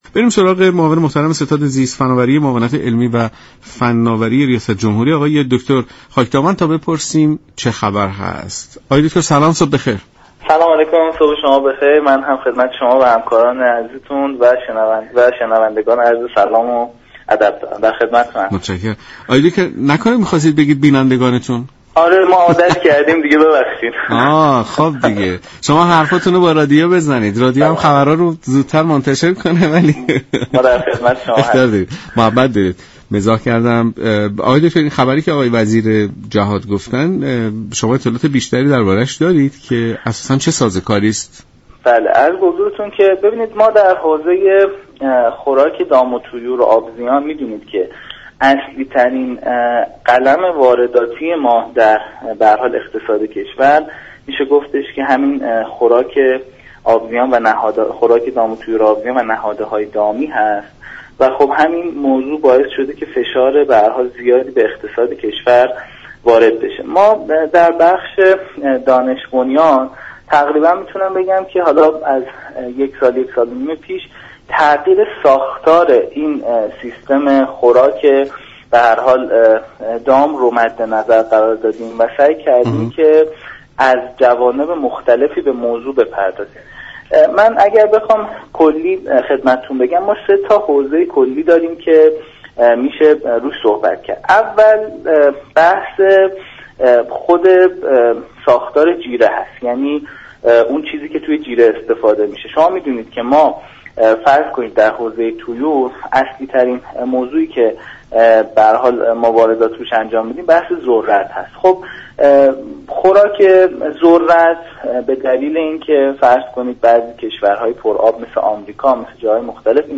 به گزارش شبكه رادیویی ایران، «علیرضا خاكدامن» معاون ستاد زیست فناوری معاونت علمی ریاست جمهوری در برنامه «سلام صبح بخیر» از فعالیت شركت های دانش بنیان برای ارزان شدن خوراك دام و طیور صحبت كرد